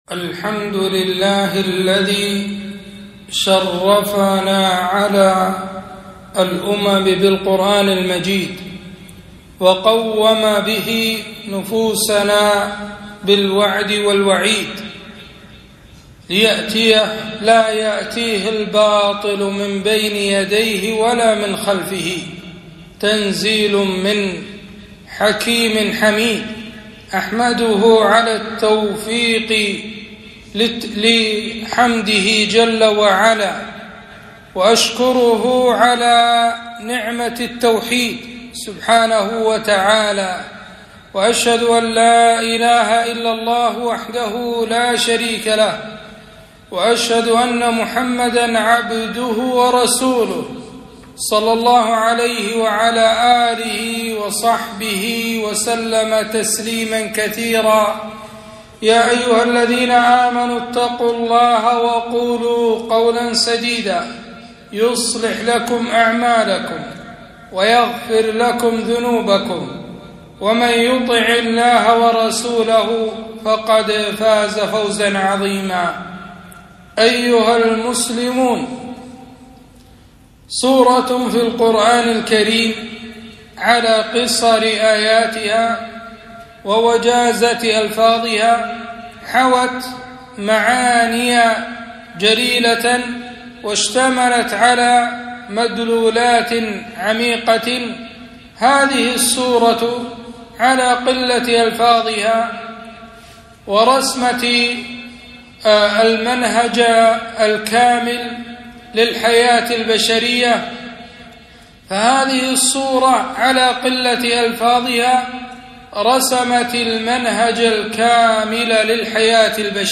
خطبة - تأملات في سورة العصر